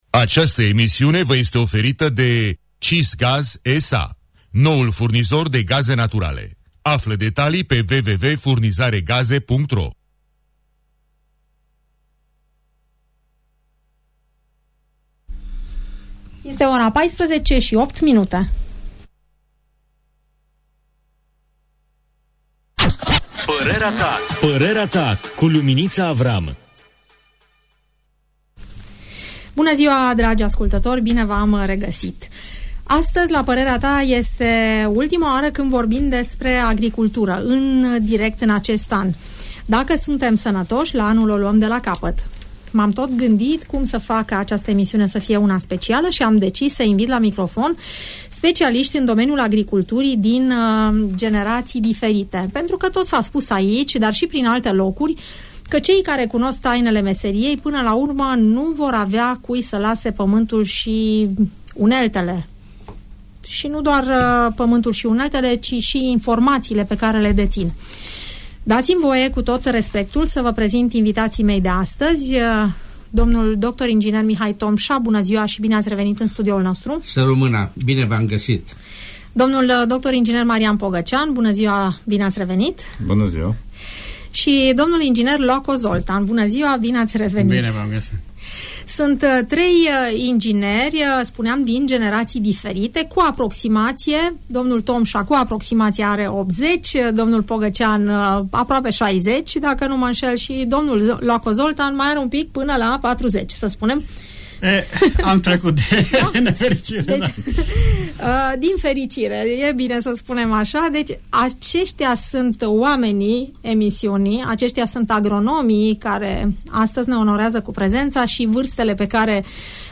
dezbat acest subiect în emisiunea „Părerea ta” de la Radio Tg Mureș